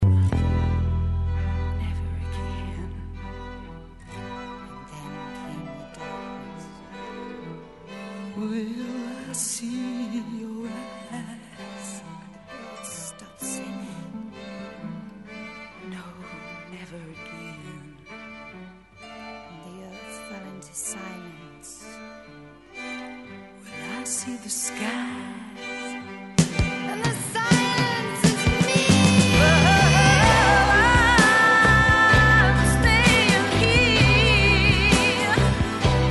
at Trident Studios, London